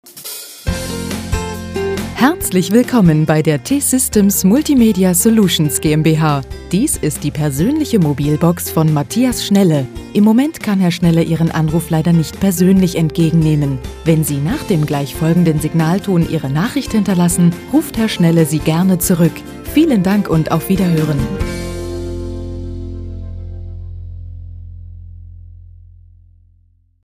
Sprecherin deutsch. Stimmalter Mitte 30. Sprecherin fĂŒr Werbung / OFF / Industriefilm / HĂ¶rspiel / Podcast
Sprechprobe: Industrie (Muttersprache):
female german voice over. Voice over artist for commercials, tv, radio, synchron, audio-books, documentaries, e-learning, podcast